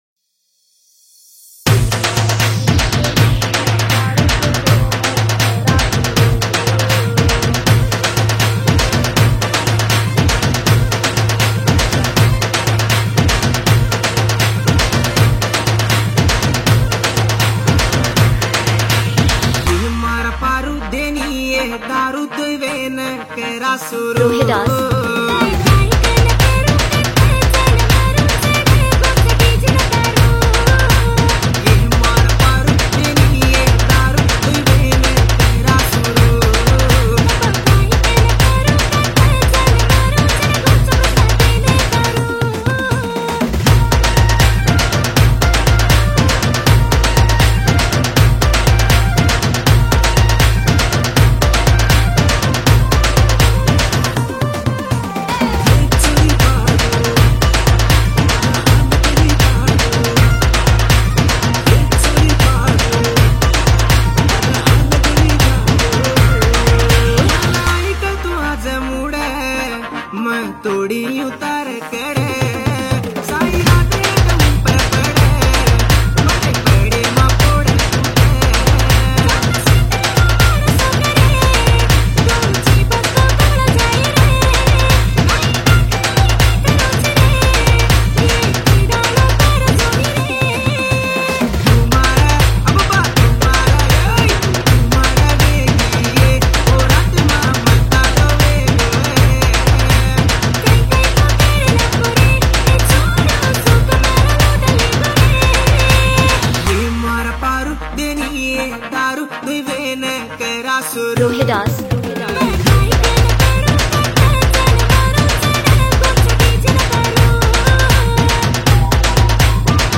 BANJARA DJ SONG 0